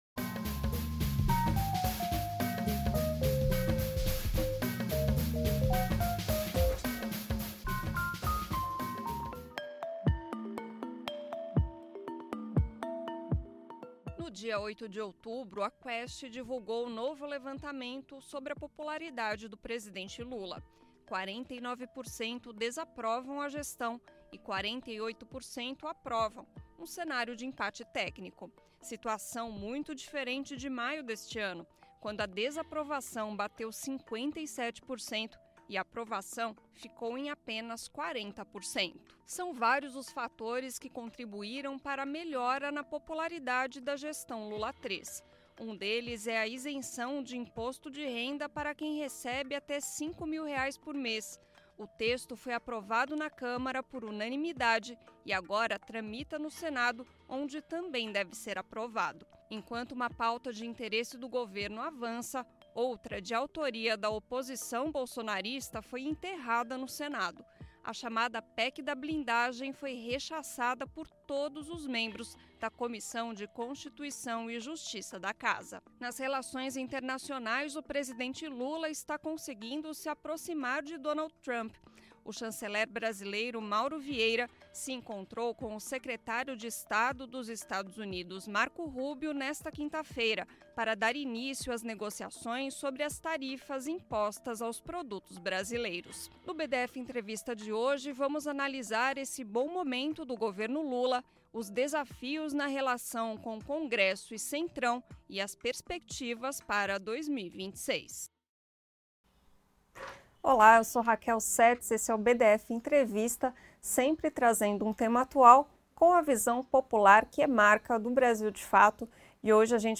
entrevistado pelo BdF Entrevista